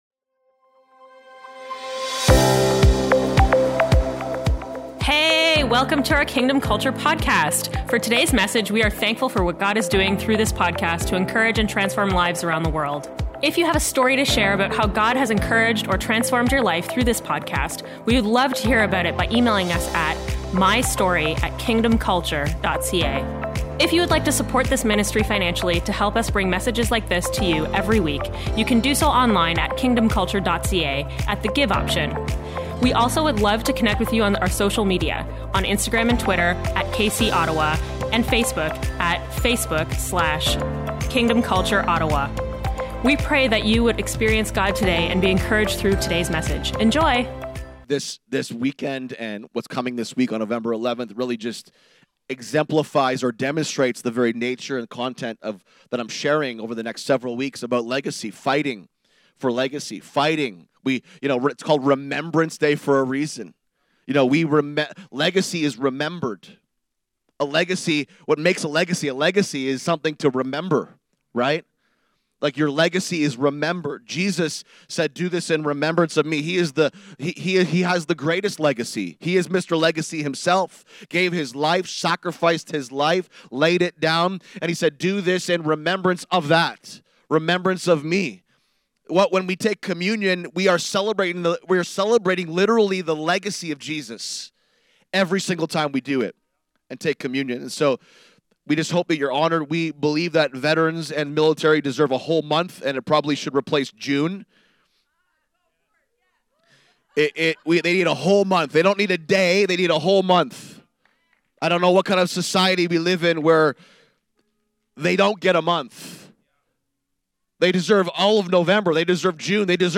Part 1 of our Fighting for Legacy Message Series.